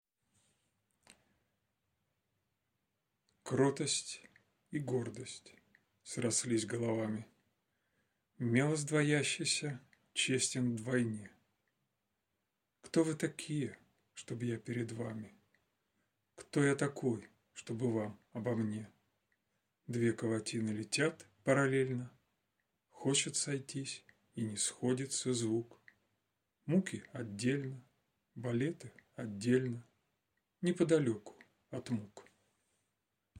читает стихотворение